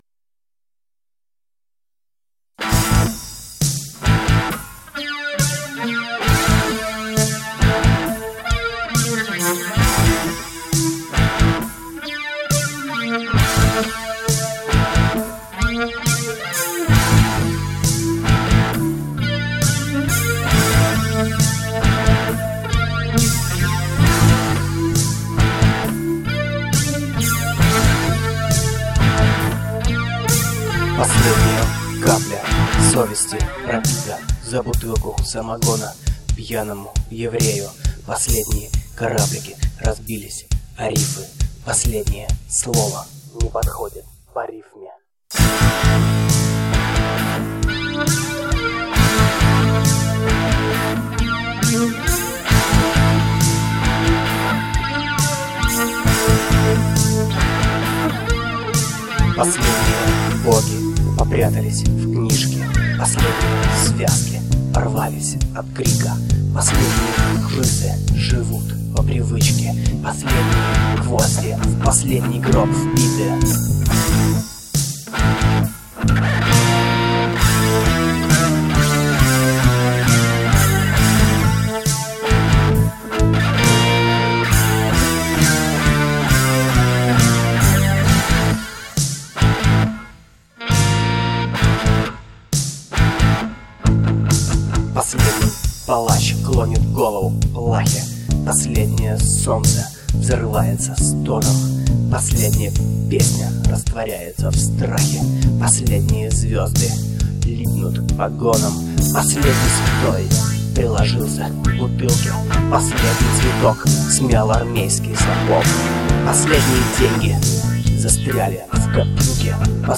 Клавиши, гитары, перкуссия, вокал